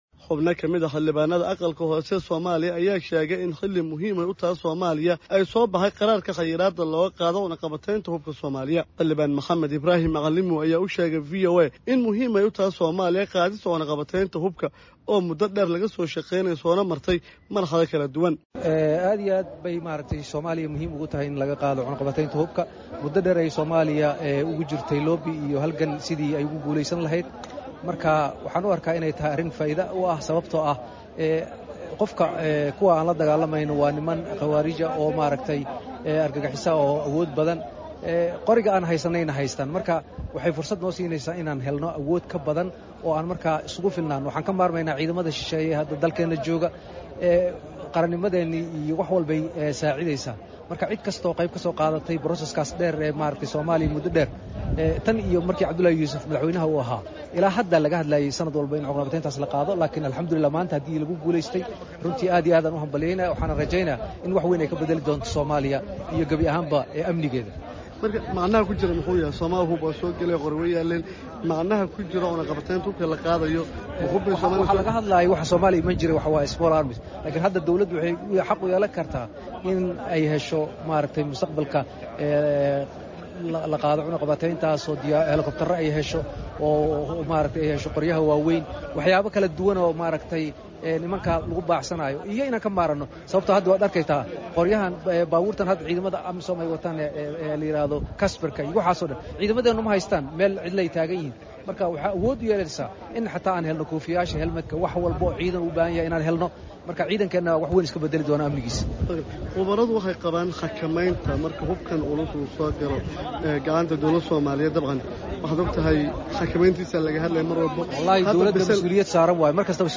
Muqdisho —